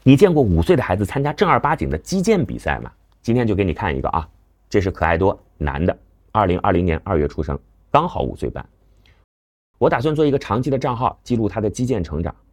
Högenergetisk AI-röst för sportvloggar med actioninnehåll
Upplev en högoktanig, tydlig AI-röst designad för engagerande sportkommentarer, spelhöjdpunkter och idrottsberättelser.
Text-till-tal
Hög energi
Tydliga kommentarer
Naturlig kadens och uttrycksfull intonation anpassad för snabb action.